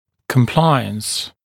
[kəm’plaɪəns][кэм’плайэнс]соблюдение (предписаний врача, правил и т.п.), согласие